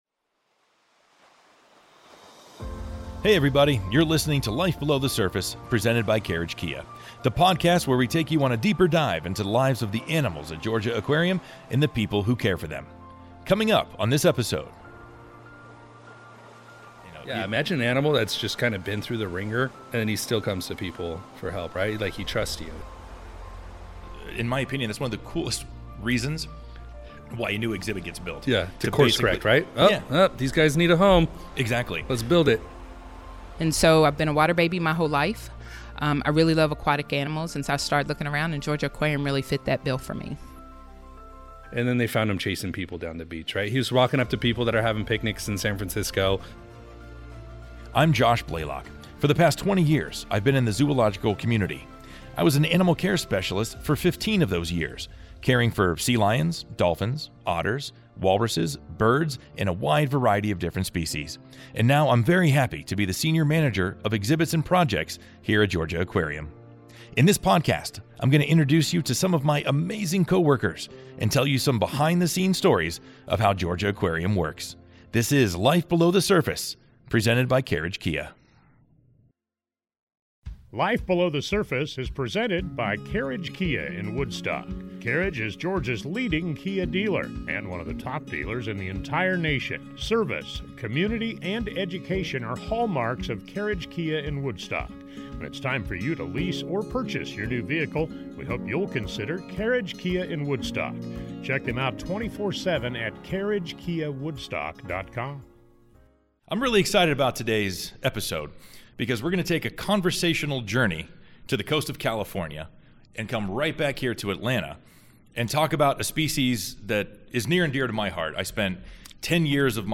Hear directly from the experts who care for our animals, travel the world, conduct important research and more behind-the-scenes stories.